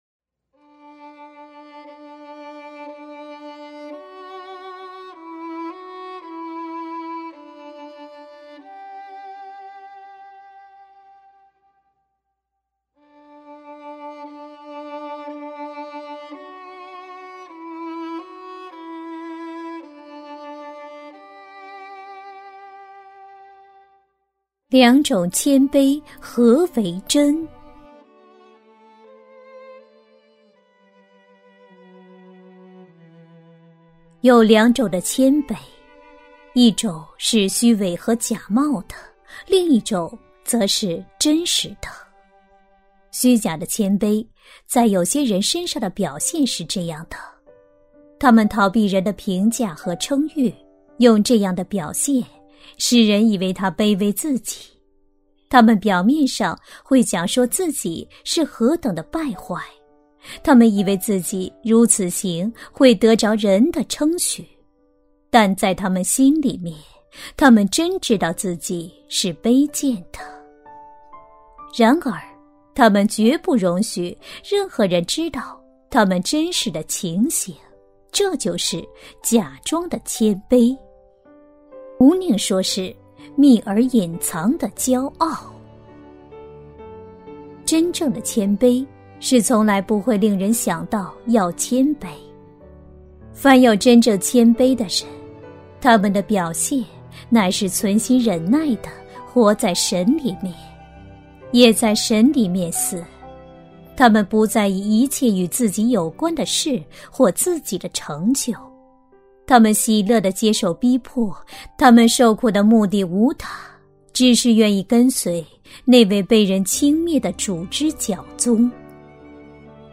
首页 > 有声书 | 灵性生活 | 灵程指引 > 灵程指引 第二十二篇:两种谦卑何为真